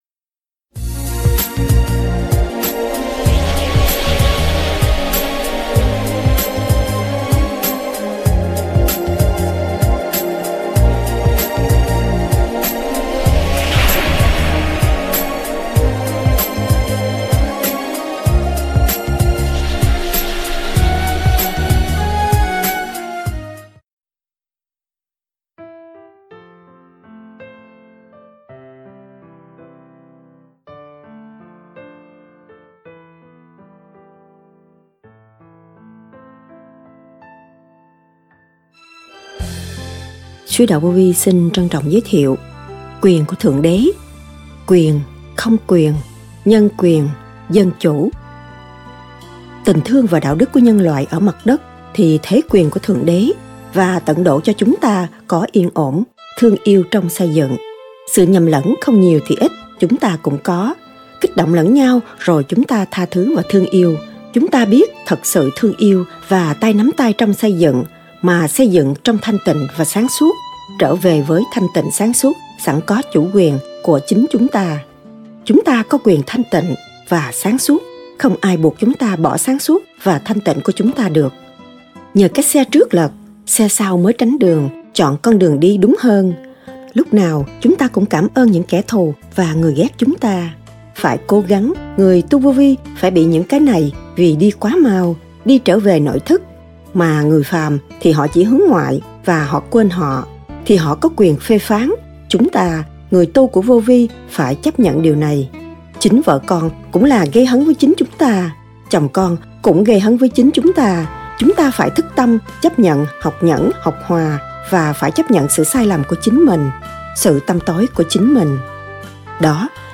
QUYỀN CỦA THƯỢNG ĐẾ-QUYỀN-KHÔNG QUYỀN-NHÂN QUYỀN-DÂN CHỦ? Lời giảng